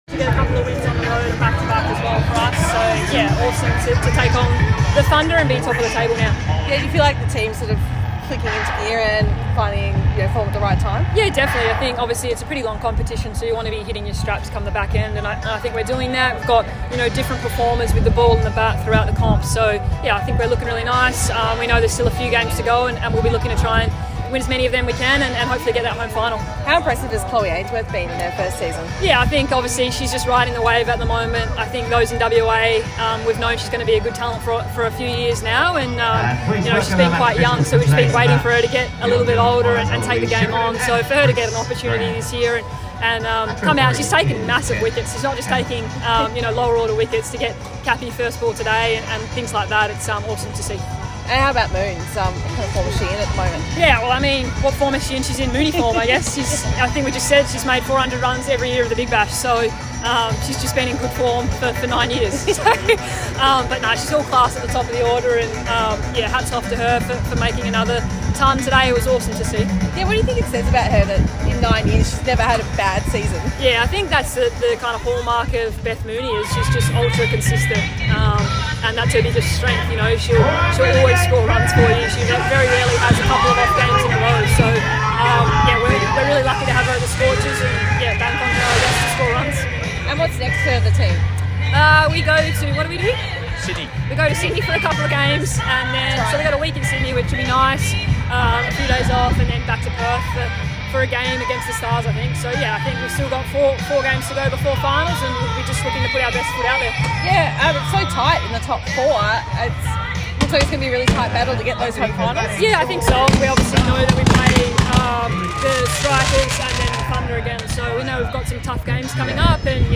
spoke to media following the Scorchers 42- run win over Melbourne Stars